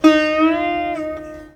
SITAR LINE59.wav